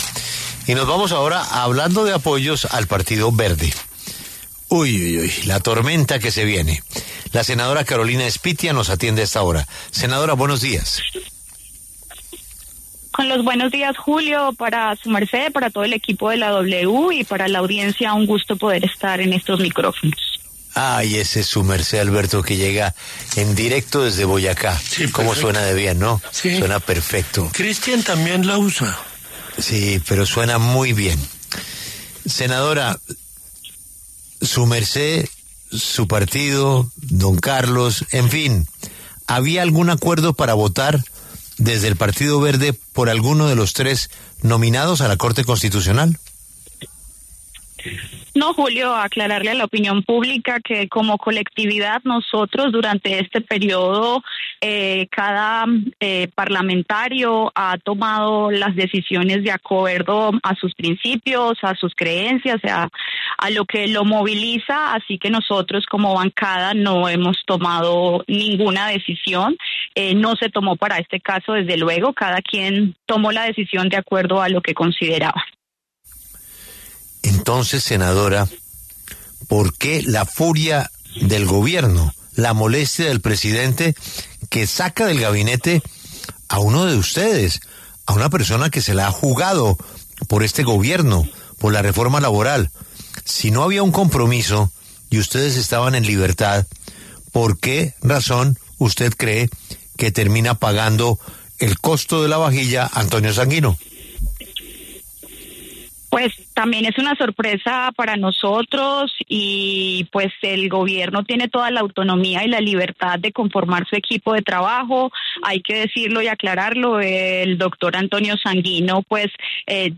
La senadora de Alianza Verde, Carolina Espitia, conversó en La W sobre cómo actuó su bancada frente a la elección de magistrado de la Corte Constitucional, y su postura personal.